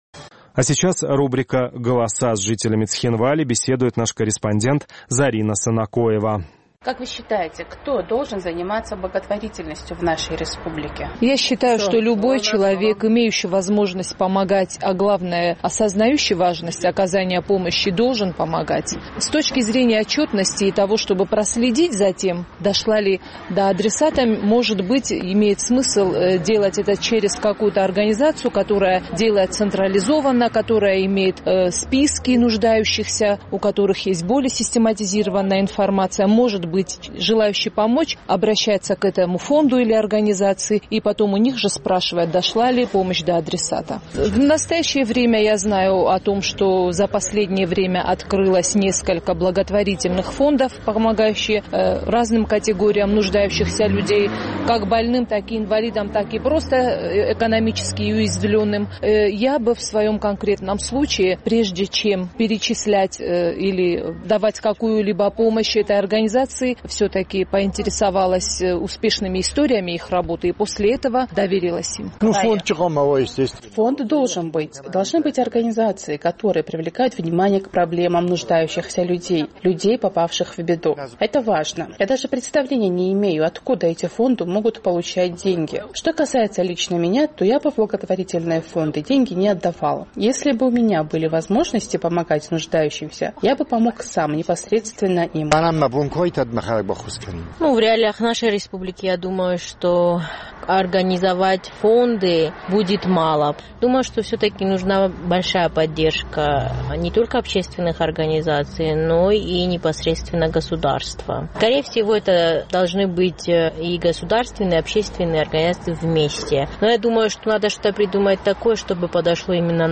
Наш цхинвалский корреспондент поинтересовалась у жителей югоосетинской столицы, кто, по их мнению, должен заниматься благотворительностью в республике.